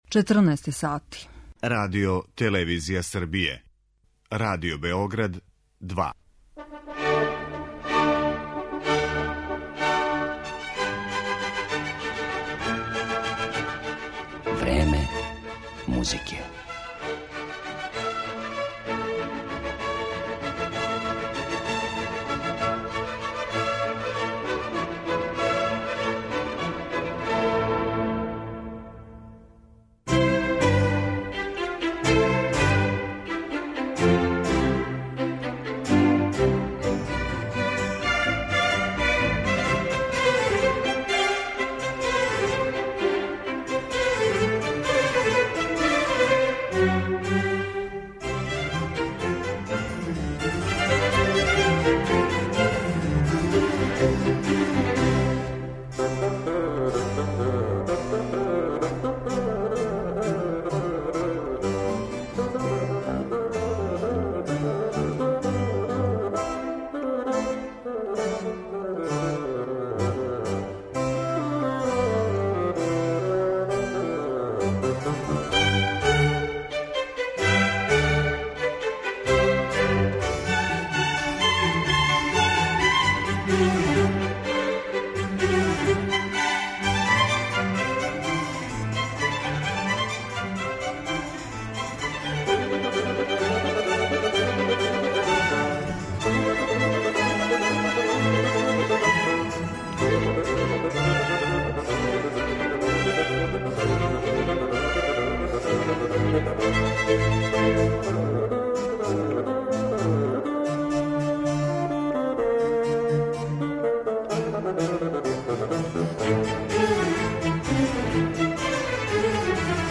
Емисију посвећујемо фаготу